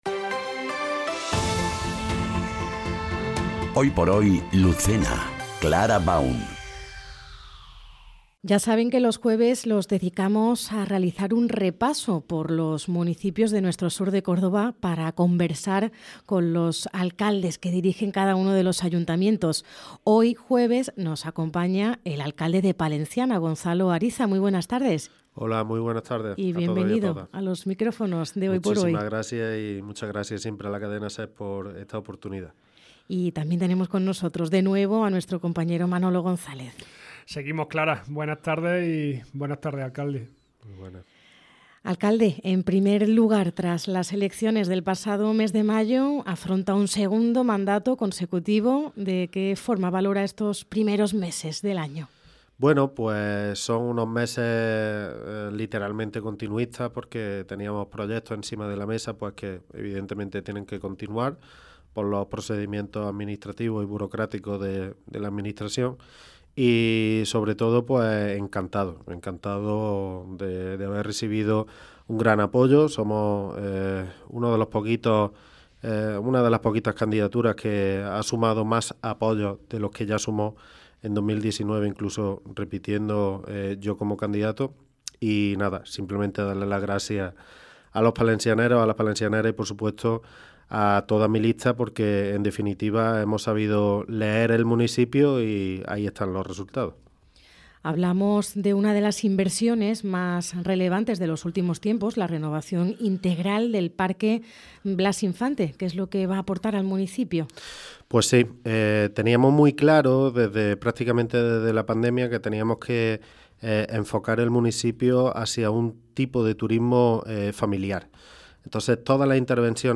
ENTREVISTA | Gonzalo Ariza, alcalde de Palenciana - Andalucía Centro